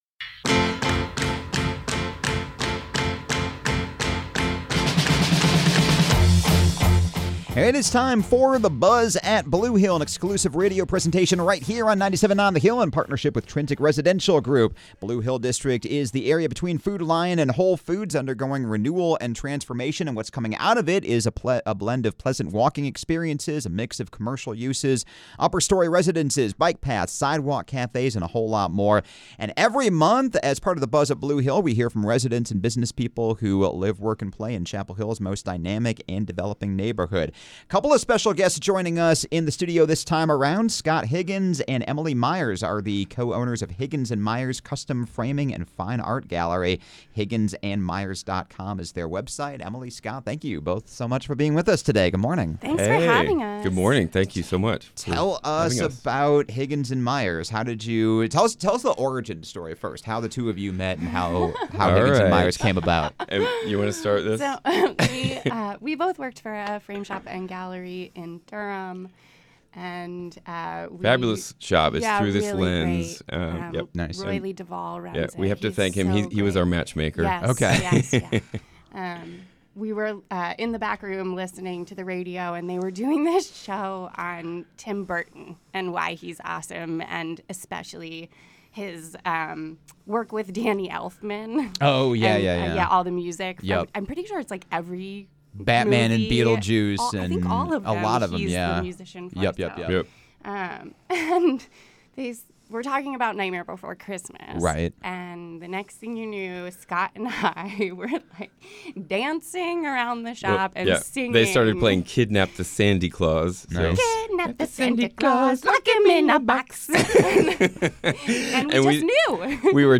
“The Buzz at Blue Hill” is an exclusive radio presentation in partnership with Trinsic Residential Group on 97.9 The Hill. Each month, a new guest will be sharing their unique perspectives and stories as people who live, work and play as part of Chapel Hill’s most dynamic and developing neighborhood.